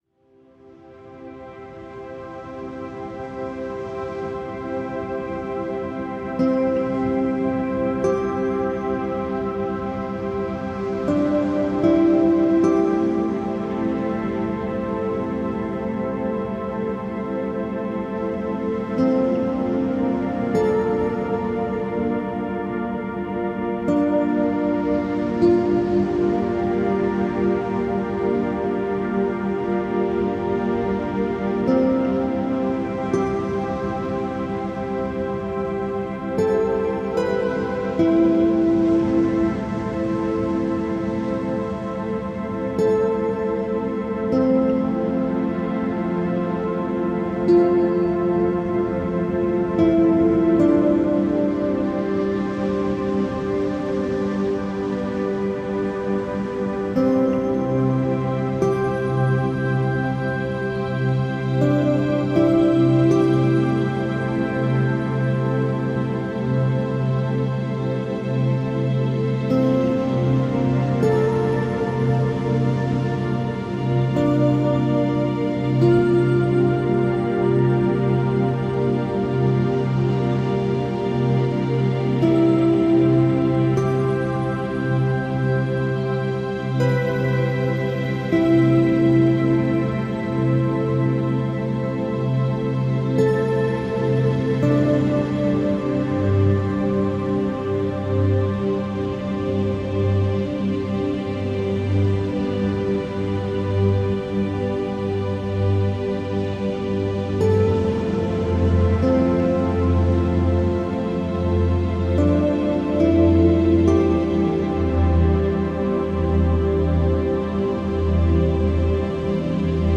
FRÉQUENCES VIBRATOIRES